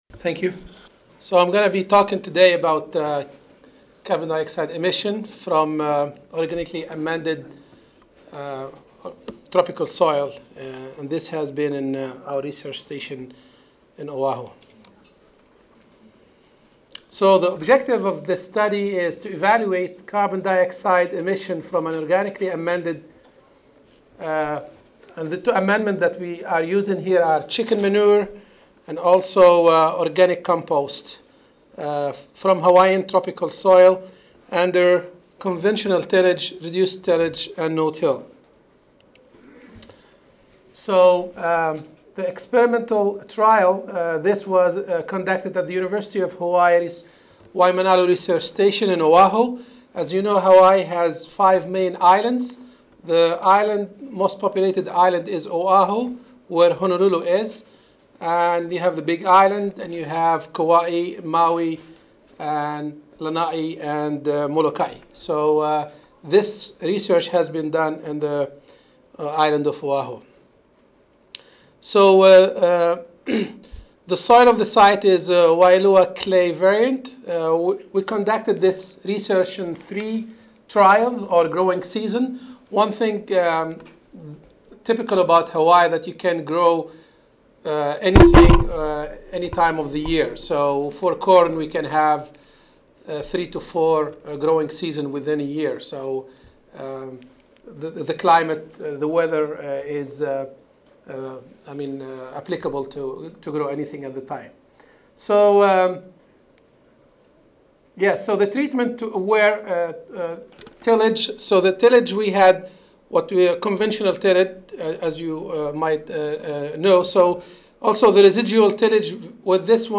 University of Hawaii Audio File Recorded presentation